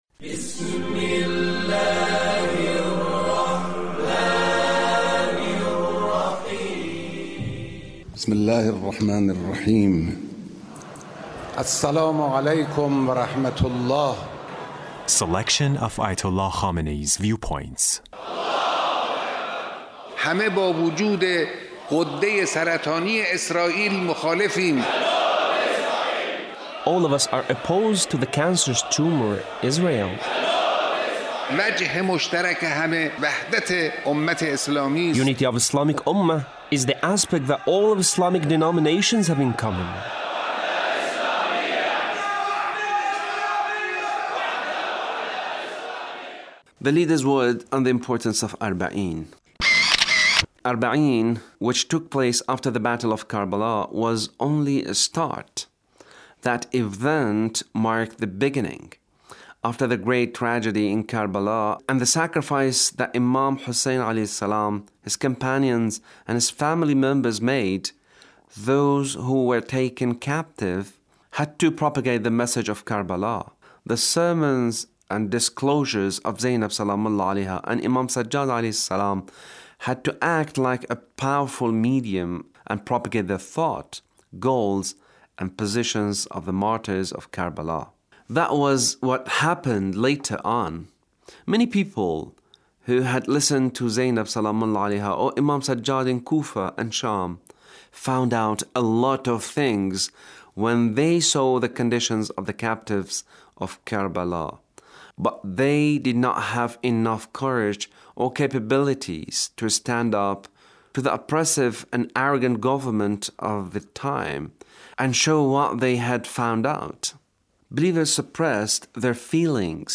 Leader's Speech on Arbaeen